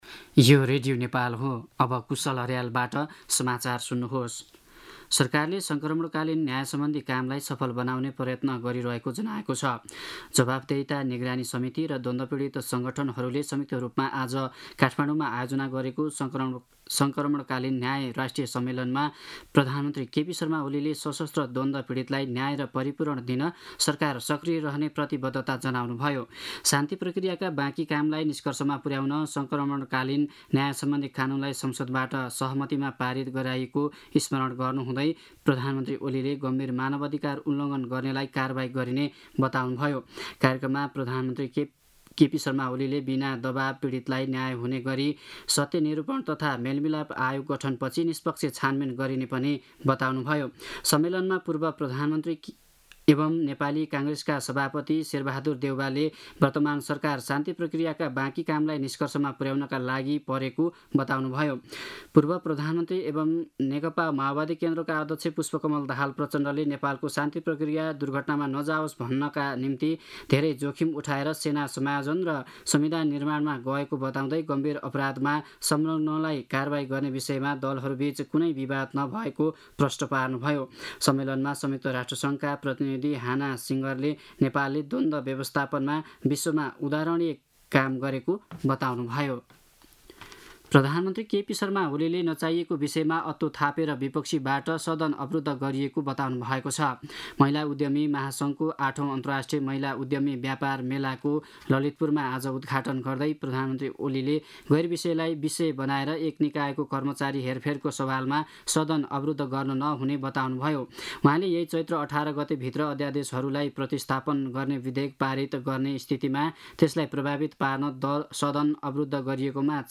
दिउँसो ४ बजेको नेपाली समाचार : १४ चैत , २०८१
4-pm-news-1-9.mp3